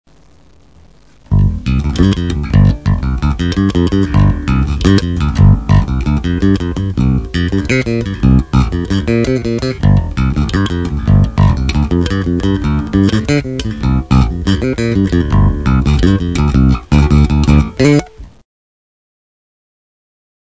15 - Bass - Groove Nr.12